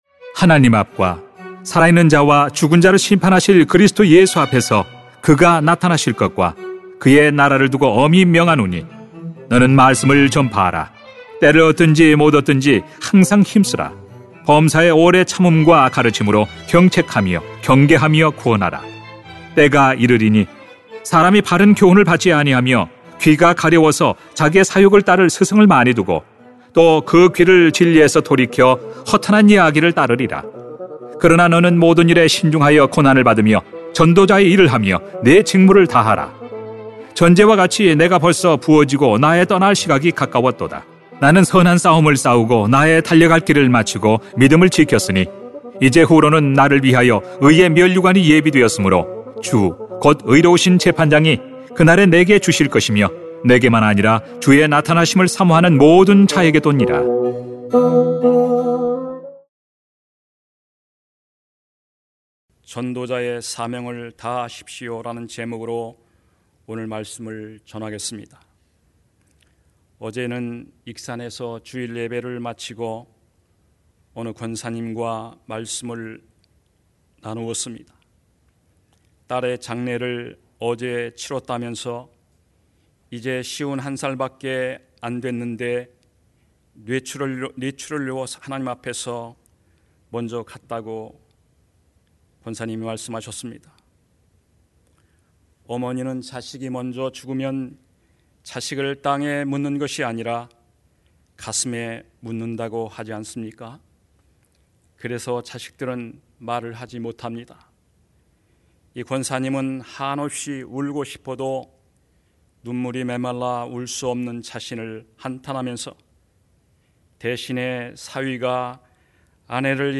[딤후 4:1-8] 전도자의 사명을 다하십시오 > 새벽기도회 | 전주제자교회